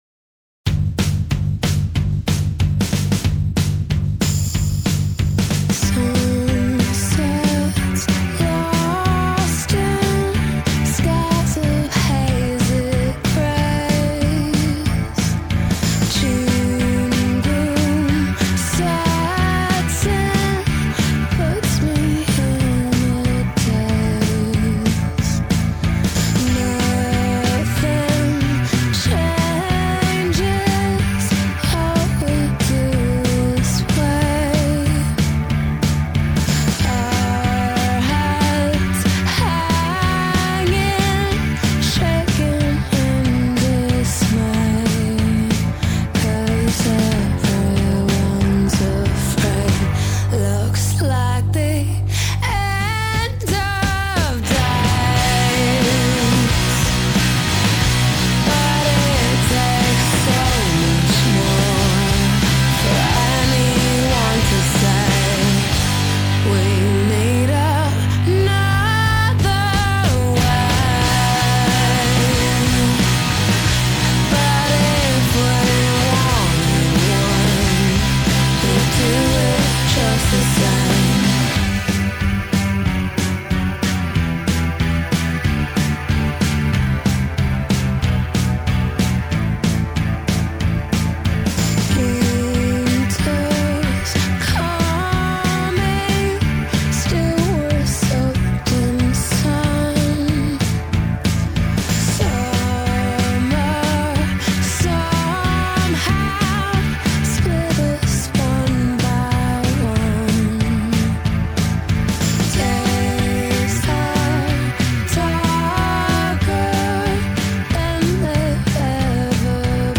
آلبوم آلترناتیو راک
Alternative Rock, Pop Rock